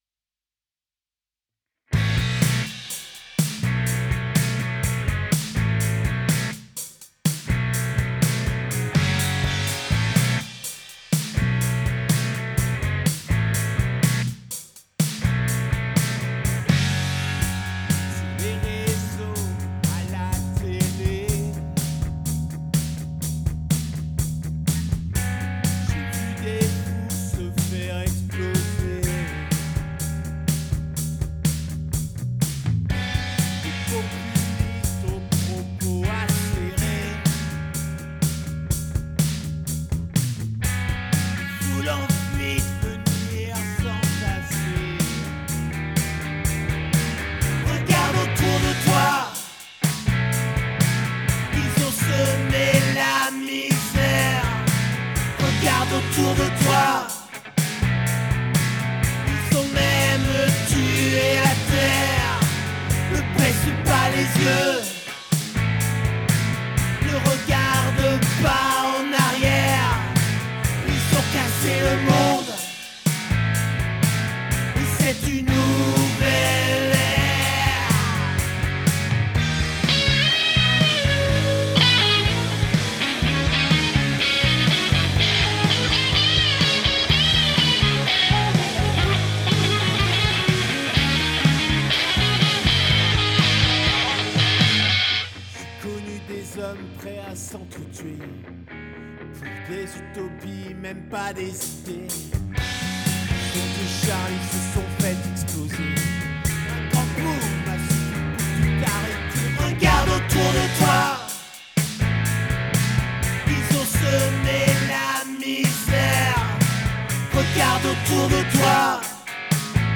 Mixed in Reaper.
These groups were then sent to a reverb.
Overall drum buss had an NLS Buss sent to reverb.
Guitars had manual volume automation.
Nice gritty punk rock.